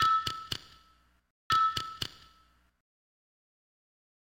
toc-toc-toc_24799.mp3